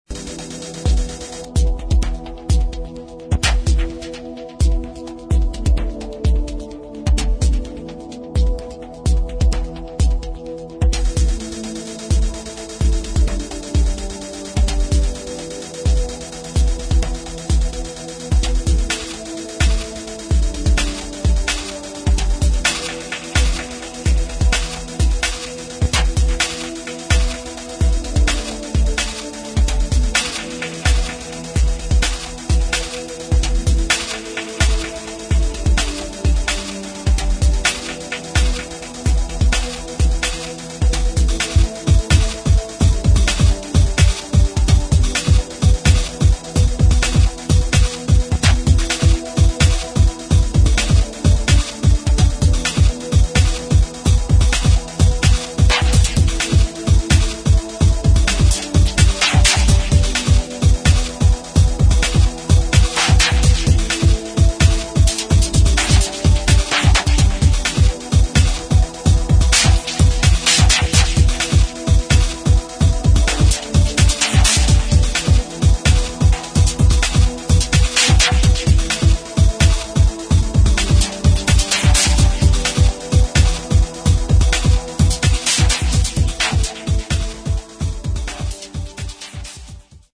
[ DEEP HOUSE / DISCO ]